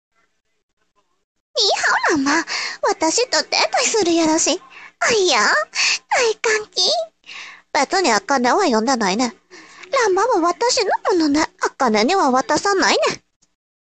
シャンプー声真似 らんま